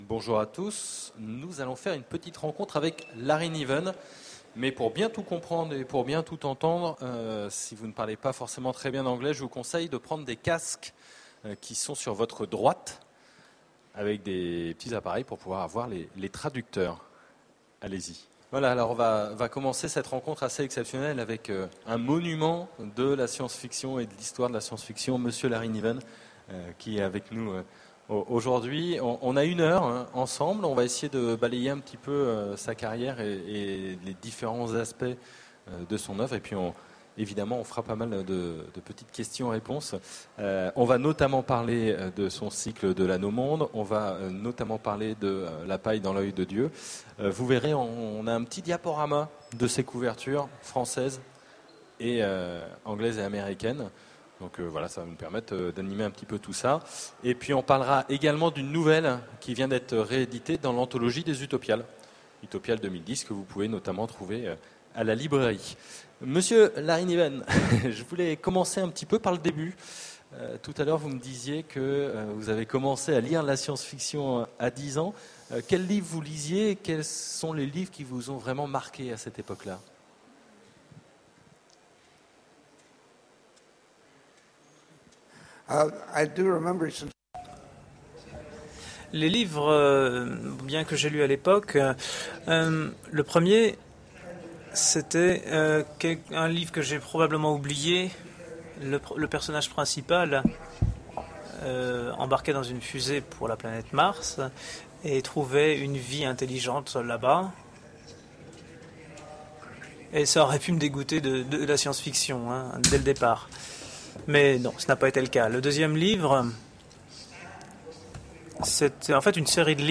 Utopiales 2010 : Conférence avec Larry Niven
Voici l'enregistrement de la rencontre avec Larry Niven aux Utopiales 2010.